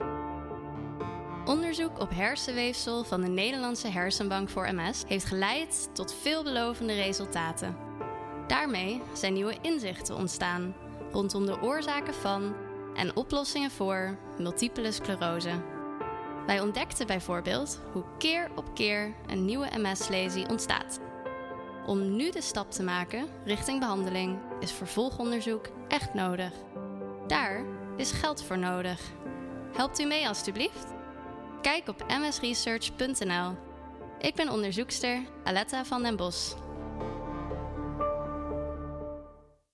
Stichting MS Research radiospot 2022
De radiocommercial is te horen van 8 t/m 21 januari op Radio 1, 2, 4, 5, 3FM, en alle regionale radiostations van Nederland.